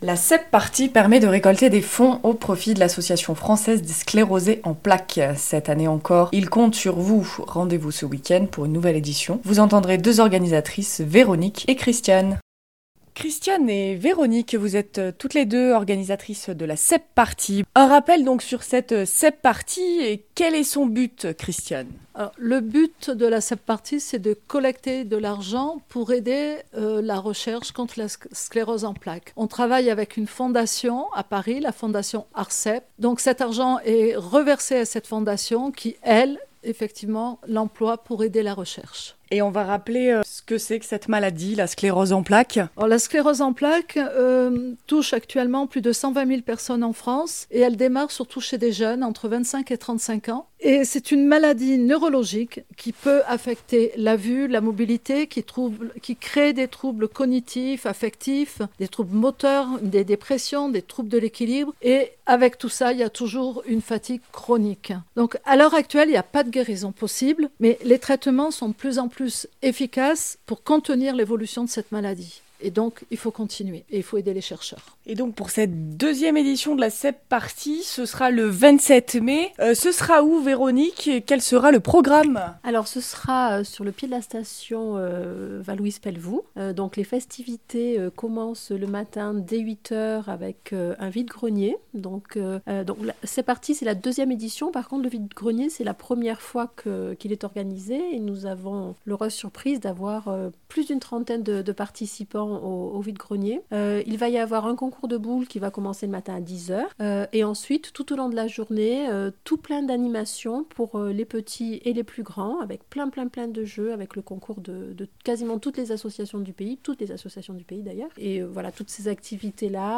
Voud entendrez 2 organisatrices